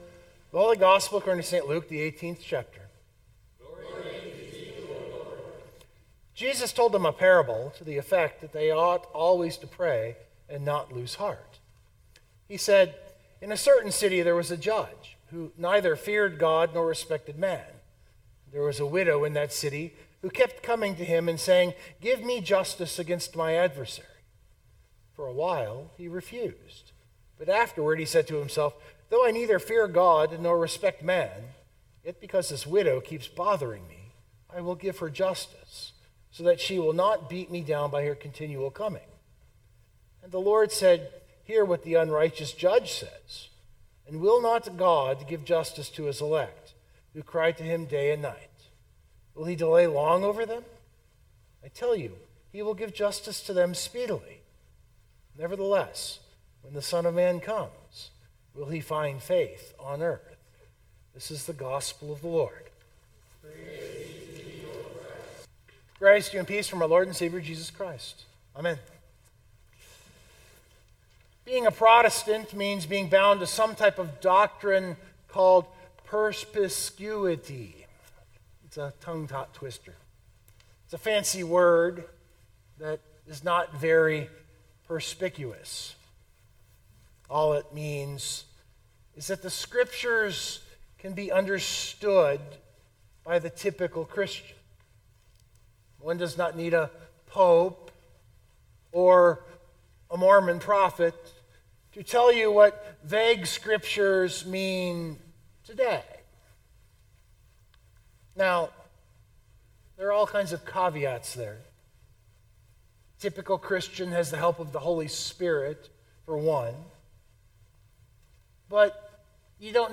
This sermon is about praying and not losing heart – keeping the faith – that God will finally deliver us from evil.